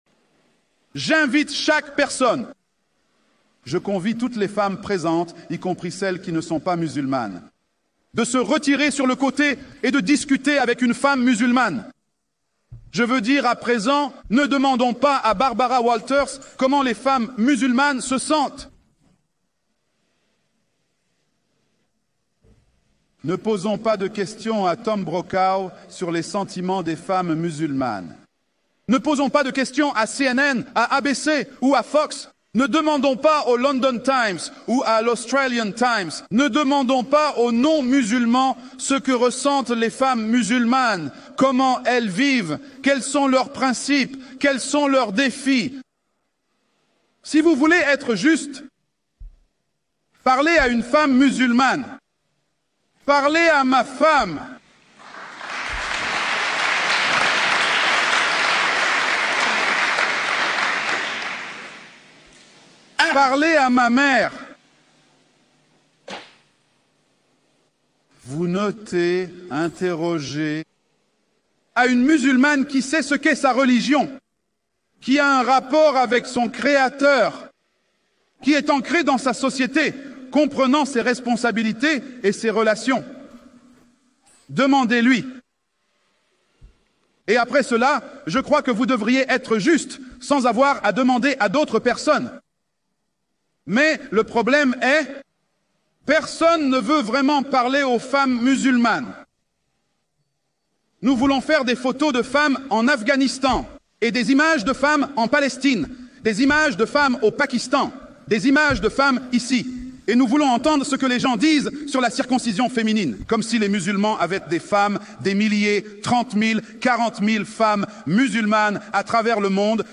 La vidéo est un magnifique extrait d'une des conférences